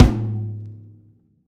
drum-hitfinish.ogg